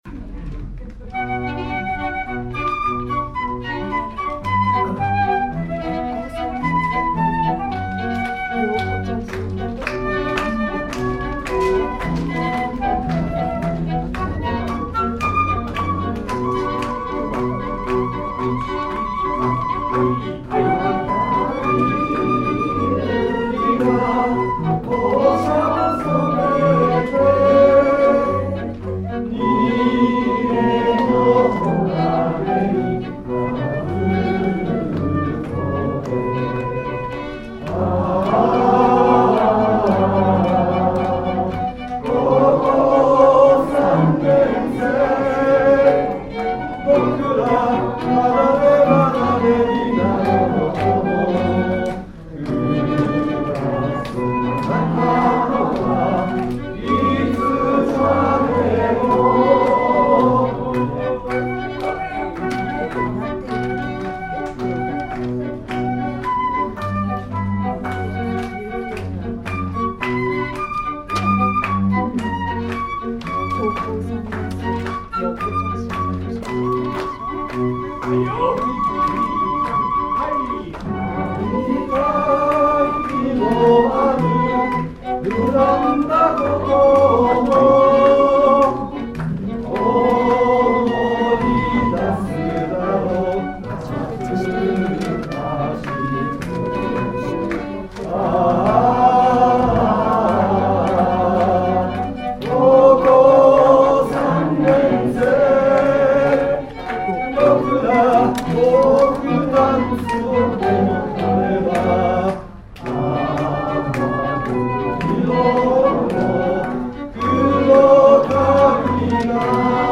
■みんなで歌いましょう
まあ、その録音を聴き返してみると、演奏自体はガタガタでしたが、やはり生の音楽というのは何かしら普通とは違う感興を呼び覚ましたのではないか、という気は、強くします。
やはり、盛り上がったのは最後のコーナーでしょうね。